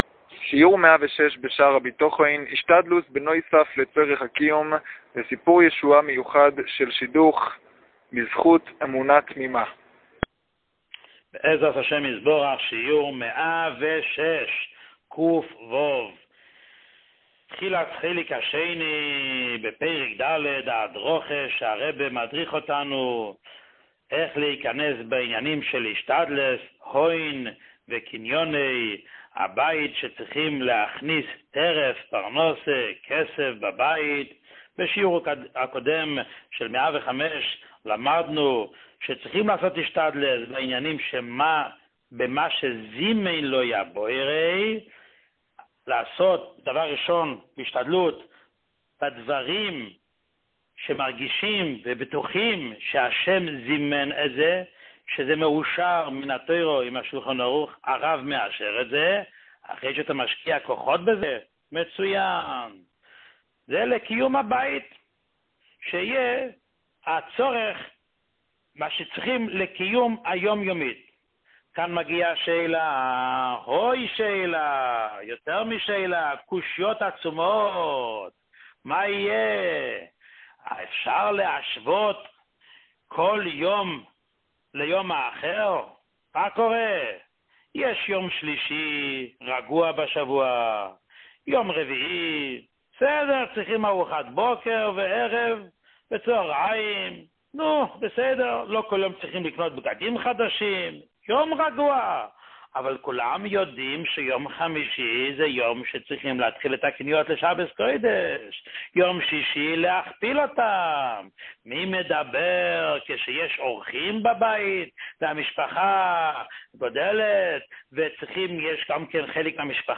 שיעור 106